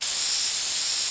spray.wav